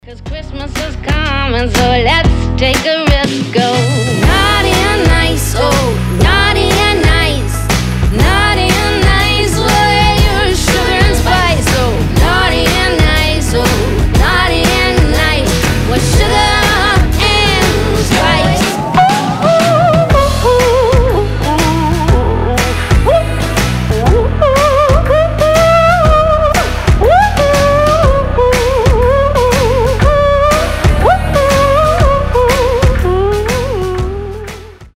• Качество: 320, Stereo
праздничные
озорные
рождественские